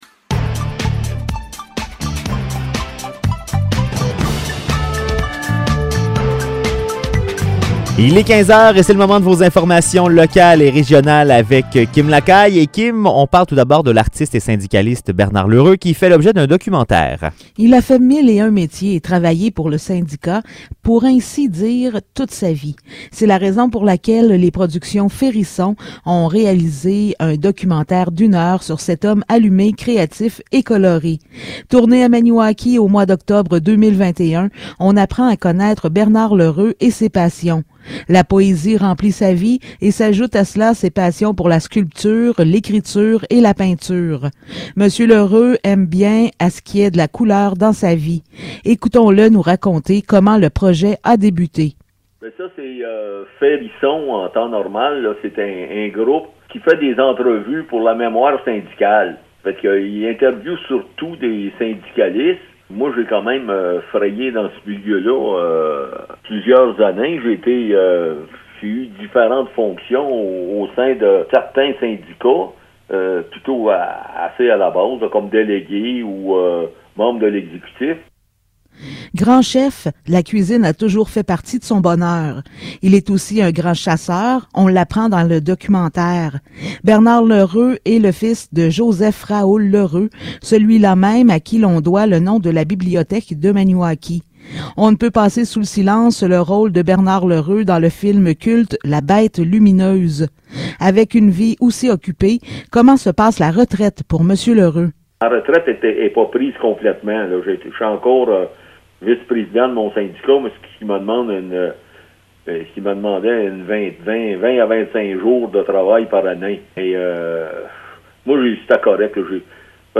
Nouvelles locales - 4 février 2022 - 15 h